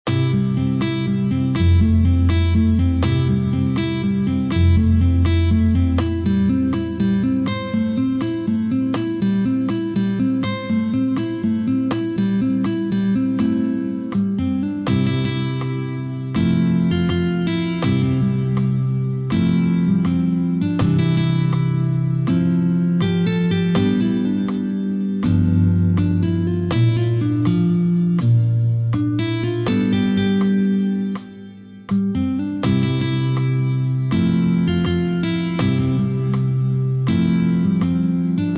Versione per sola chitarra